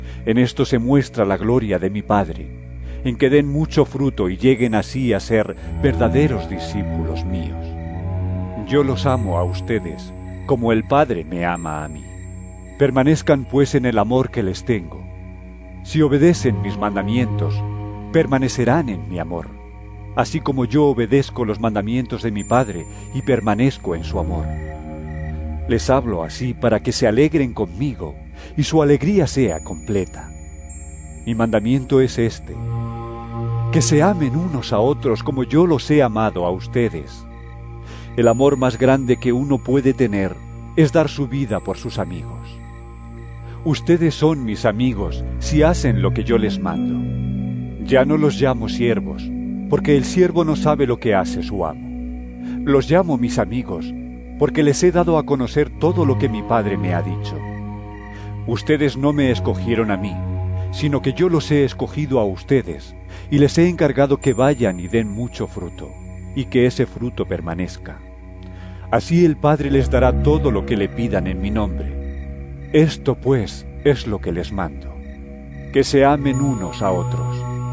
Jn 15 12-17 EVANGELIO EN AUDIO